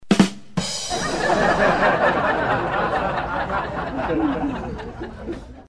Звуки бадум тсс
2. Бадум тсс и хохот